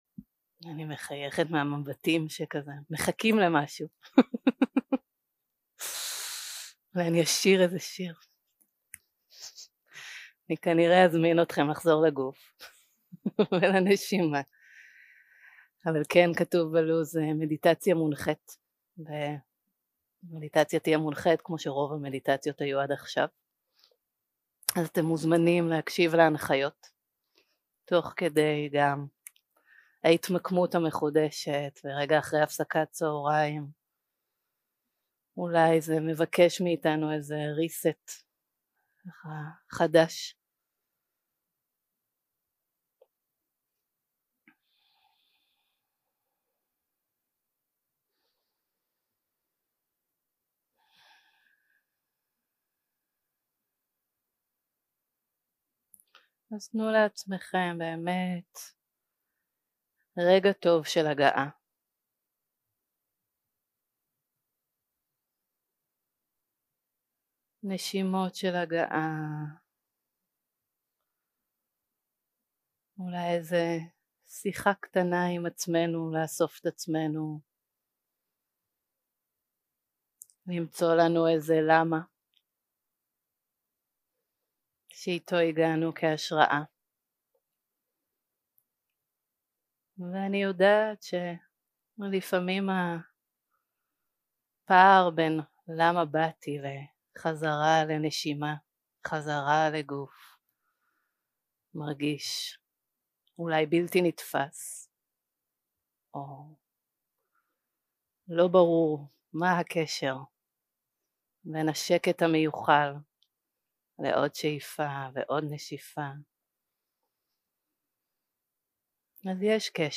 יום 2 – הקלטה 3 – צהריים – מדיטציה מונחית – מפגש עם האורחים 0:00 0:00 סוג ההקלטה: Dharma type: Guided meditation שפת ההקלטה: Dharma talk language: Hebrew